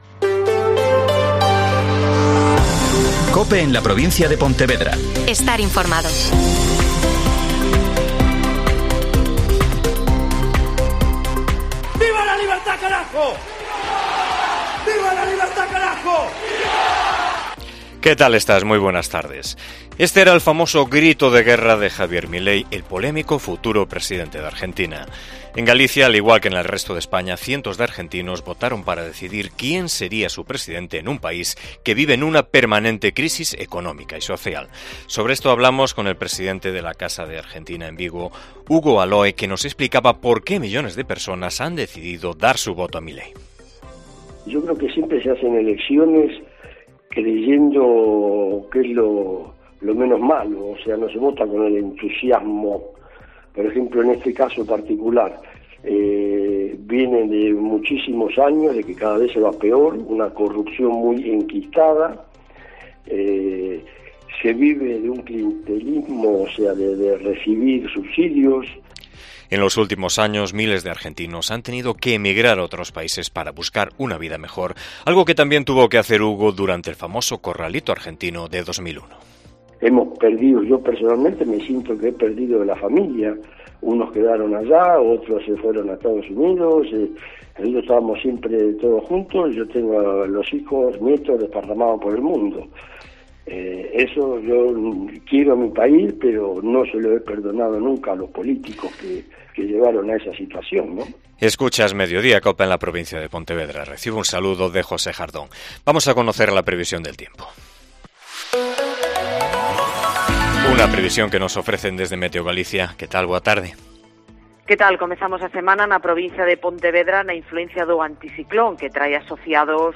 AUDIO: Informativo provincial